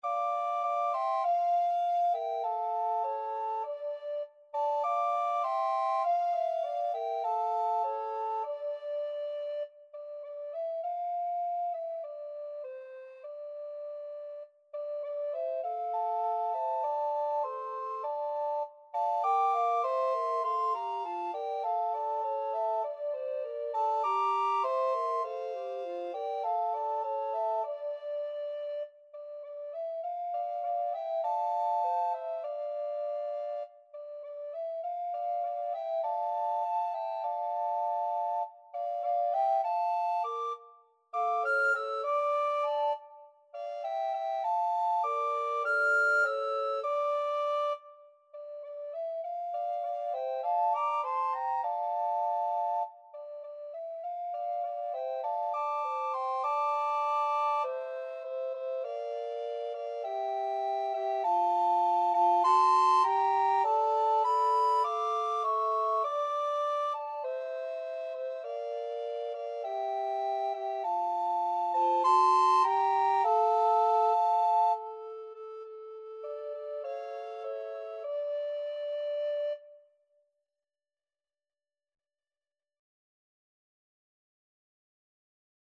D minor (Sounding Pitch) (View more D minor Music for Recorder Trio )
Moderato
Recorder Trio  (View more Intermediate Recorder Trio Music)
Classical (View more Classical Recorder Trio Music)